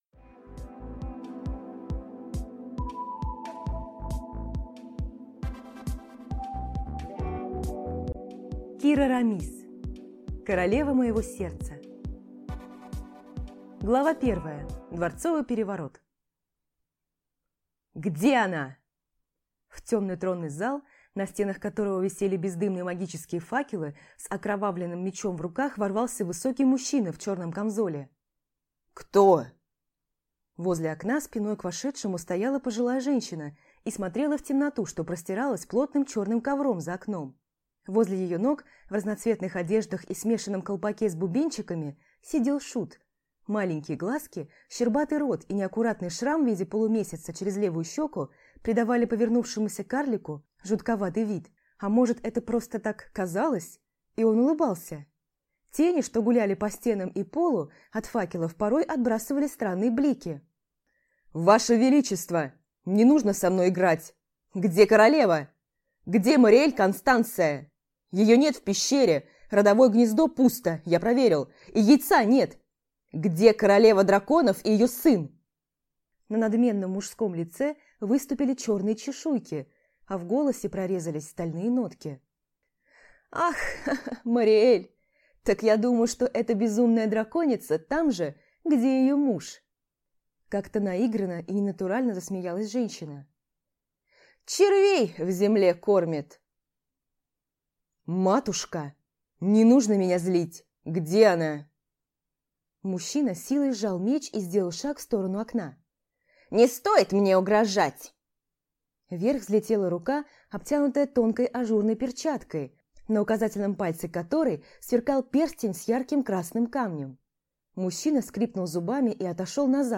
Аудиокнига Королева моего сердца | Библиотека аудиокниг